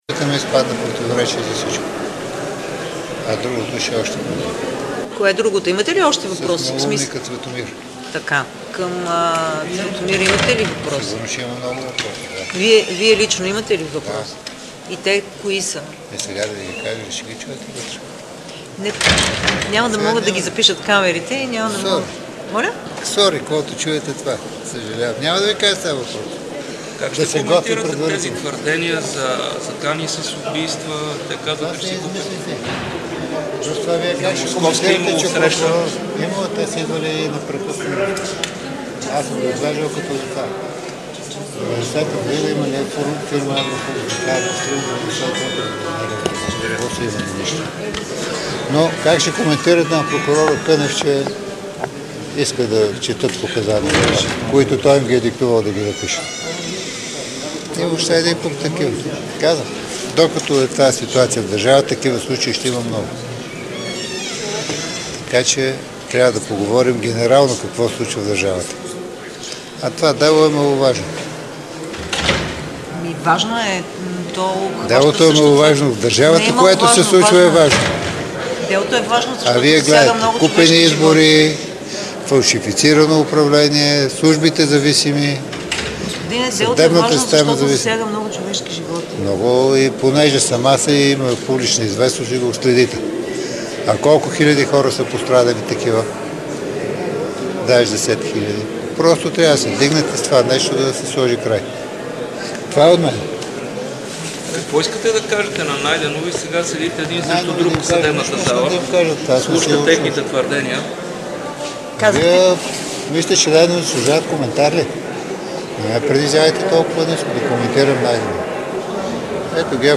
11.20 -  Заседание на Министерски съвет.
- директно от мястото на събитието (Министерски съвет)